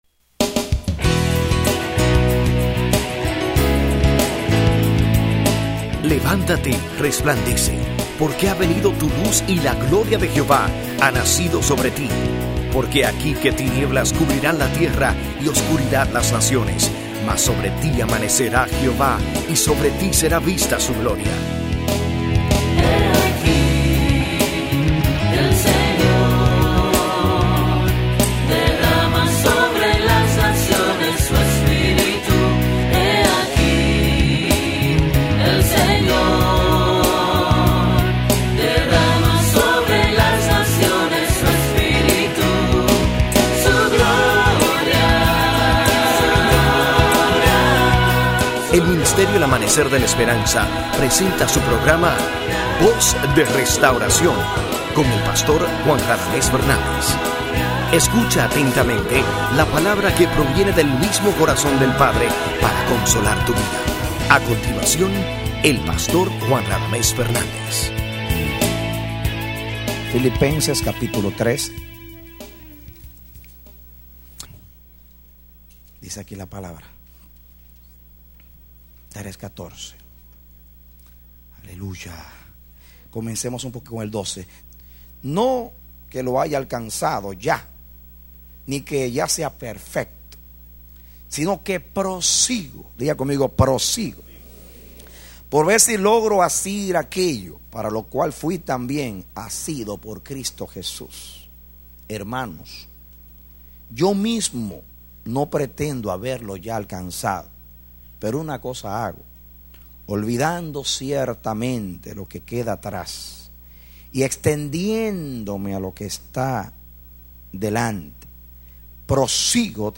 Predicado Marzo 18, 2001